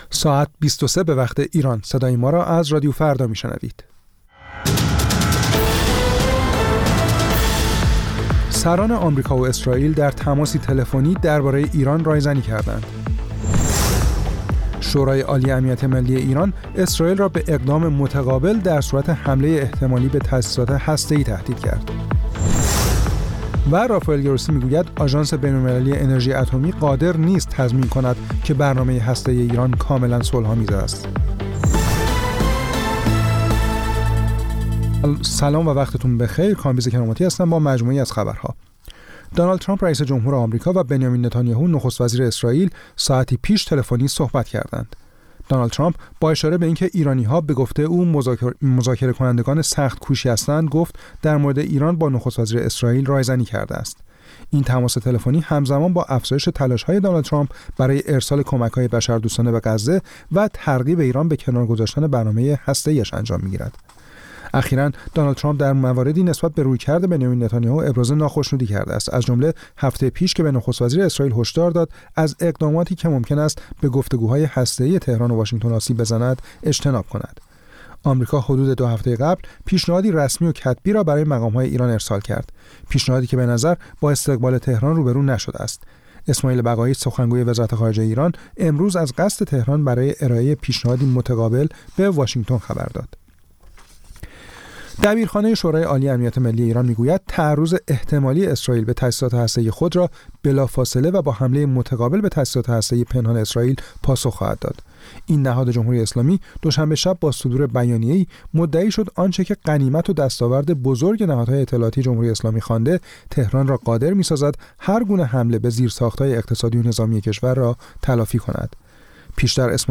سرخط خبرها ۲۳:۰۰